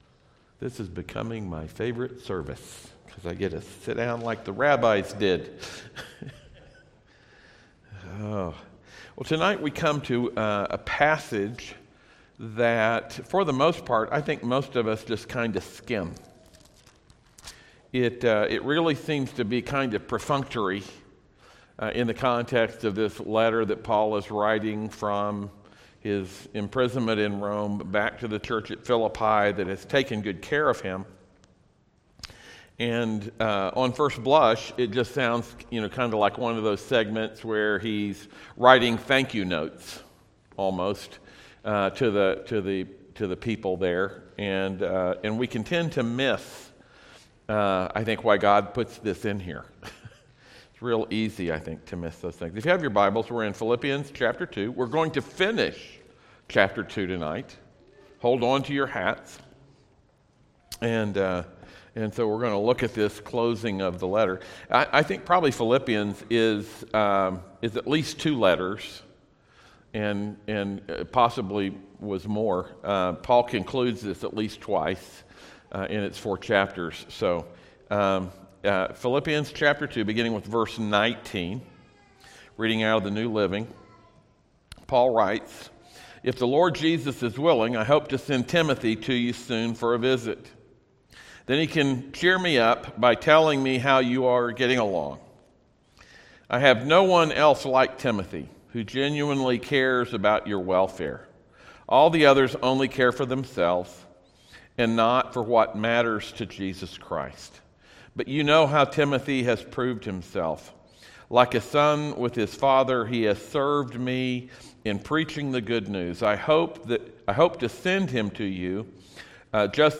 Service Type: audio sermons